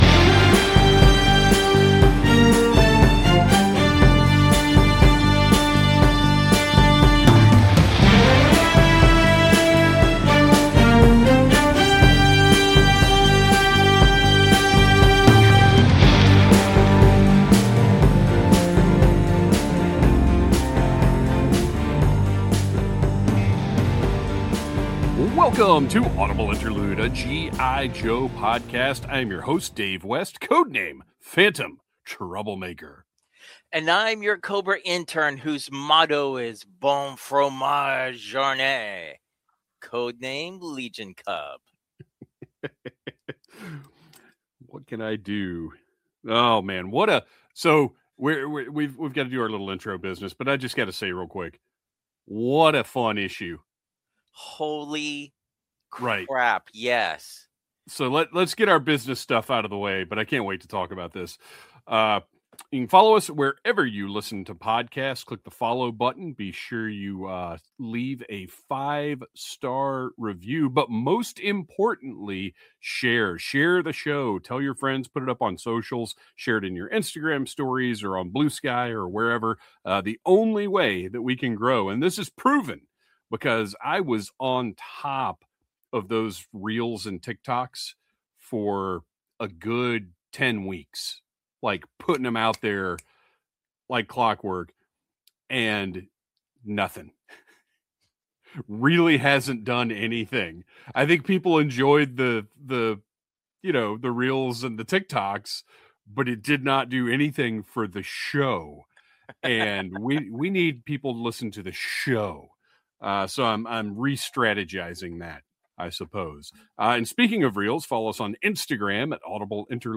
We're recruiting you to listen to this podcast hosted by three lifelong Joe fans.